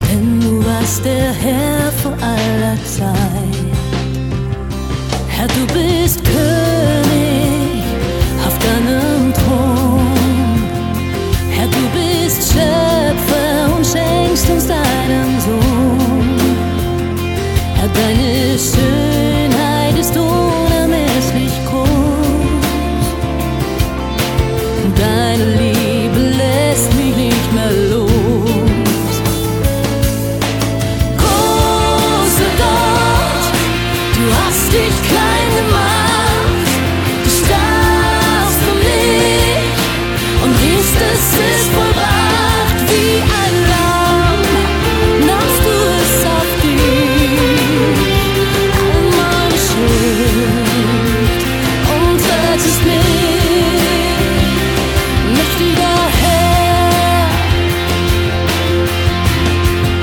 Lieder für Momente der Anbetung
Lobpreis